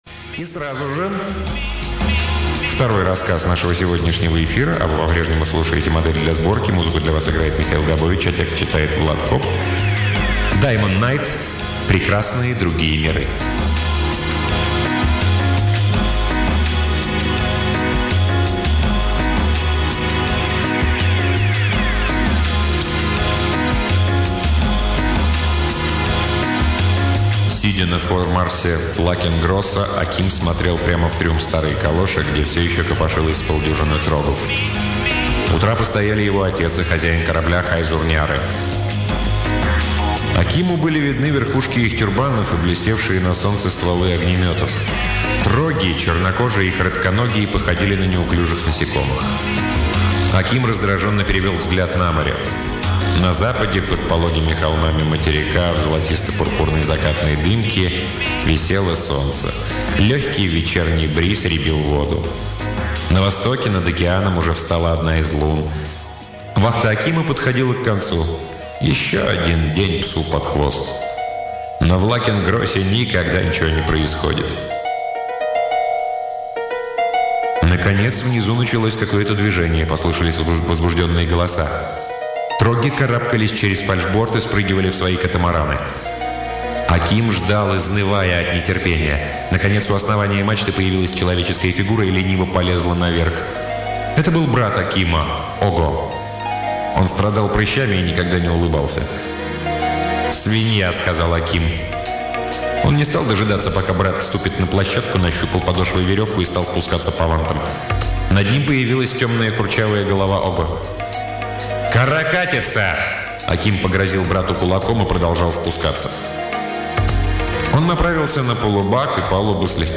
Аудиокнига Даймон Найт — Прекрасные другие миры